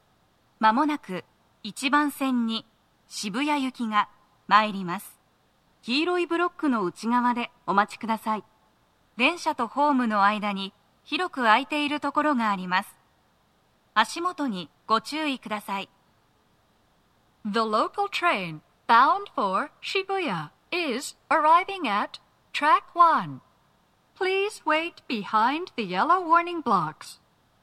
スピーカー種類 TOA天井型
鳴動は、やや遅めです。
1番線 渋谷方面 接近放送 【女声